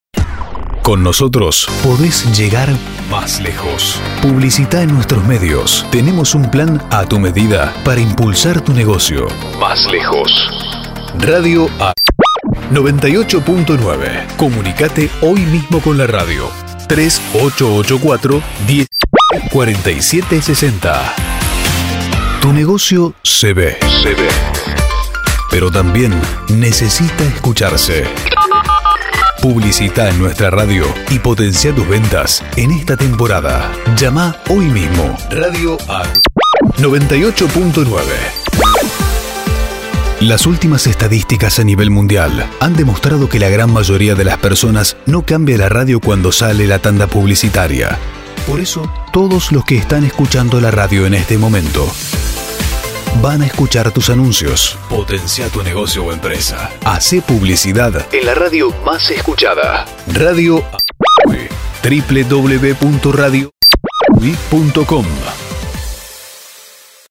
Spots con los mejores argumentos para que los comercios, empresas y/o profesionales de tu ciudad sepan por qué deben publicitar en tu radio.